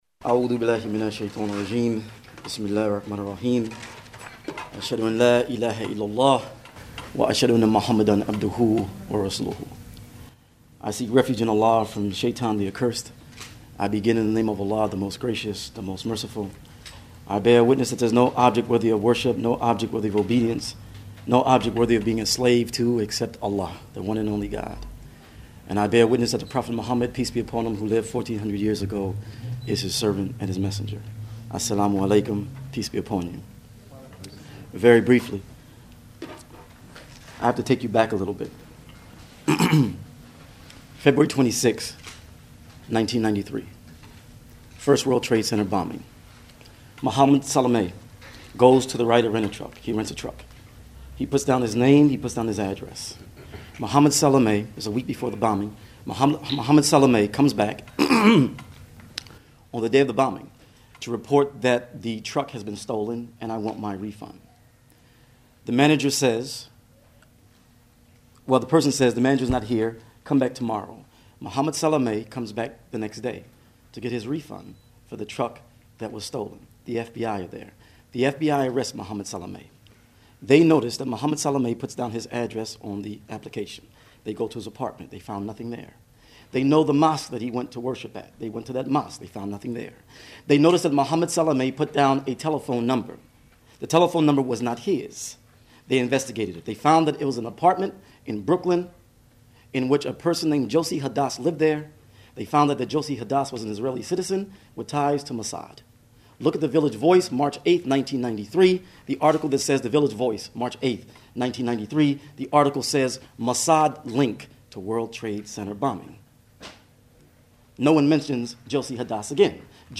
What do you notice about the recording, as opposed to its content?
The East Bay Coalition Against the War held a forum at Laney College in Oakland on Nov. 4.